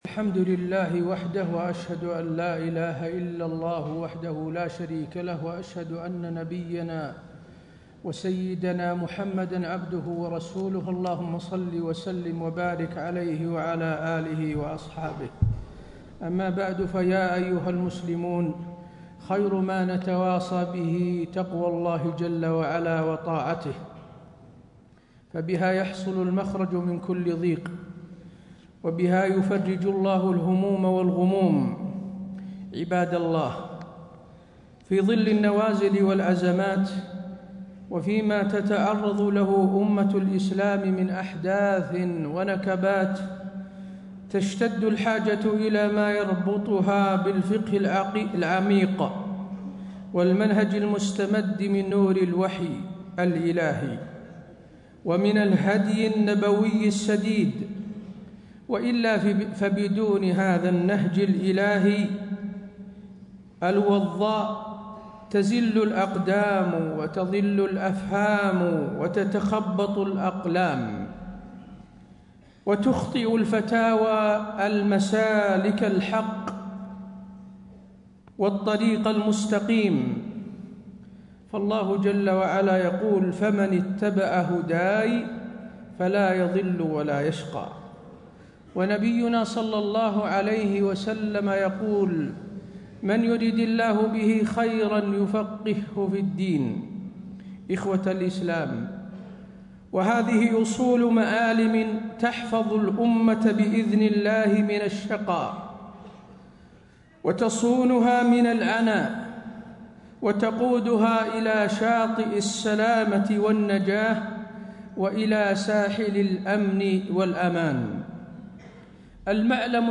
تاريخ النشر ٢١ ربيع الثاني ١٤٣٥ هـ المكان: المسجد النبوي الشيخ: فضيلة الشيخ د. حسين بن عبدالعزيز آل الشيخ فضيلة الشيخ د. حسين بن عبدالعزيز آل الشيخ معالم حفظ الأمة من الفتن